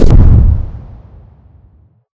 gravhit.ogg